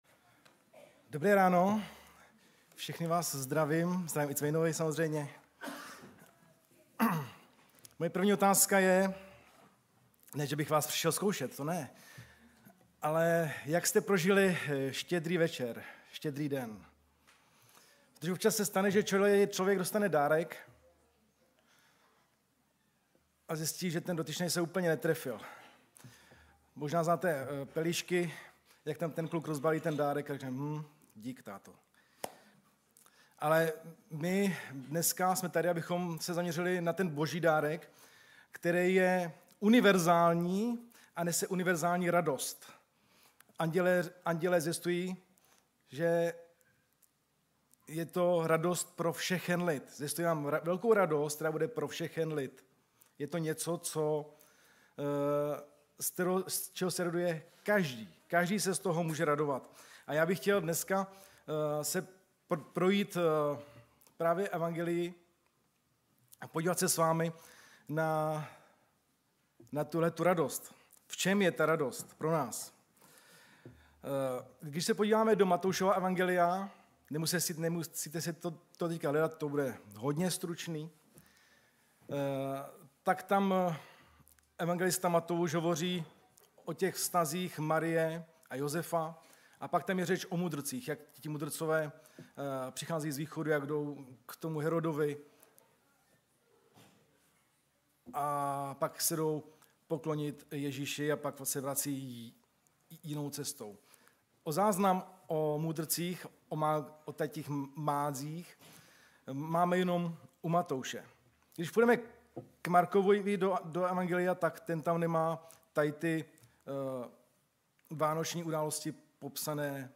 Vánoční bohoslužba
Nedělní bohoslužby